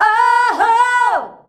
OOOHOO  B.wav